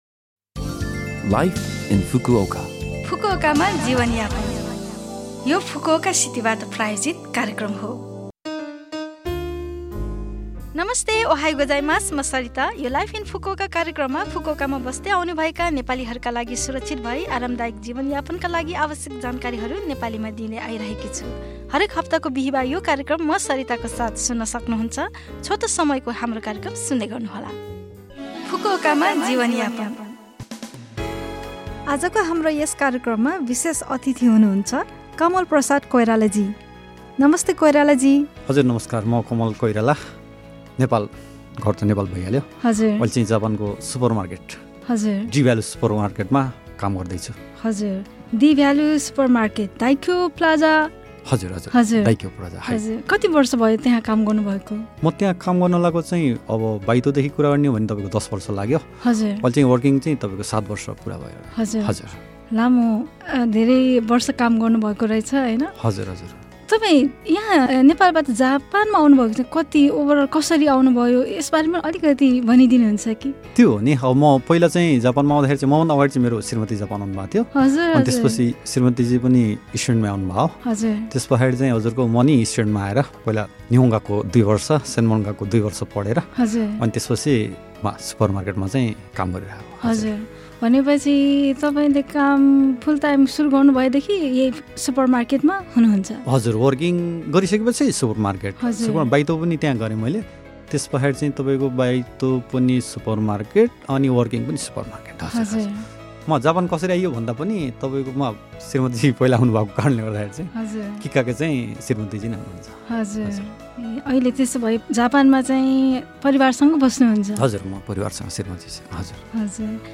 #287 अतिथि अन्तर्वार्ता